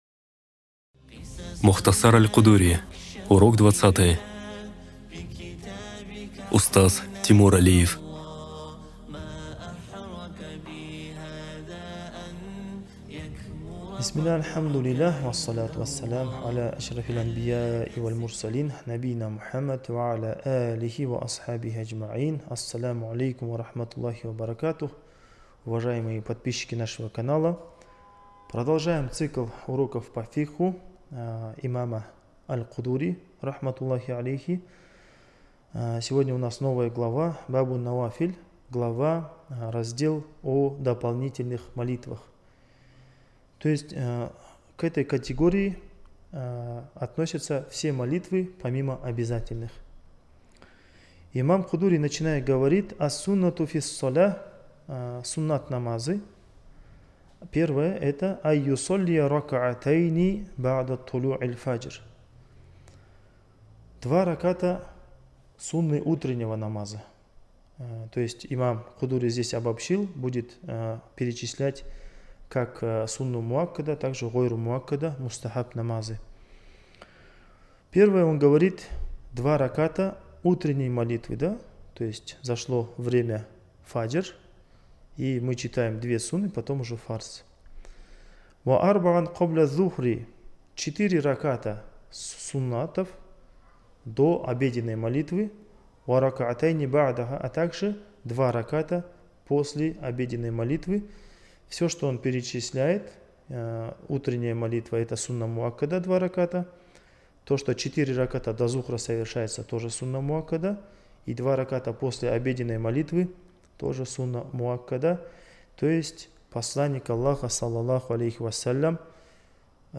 Циклы уроков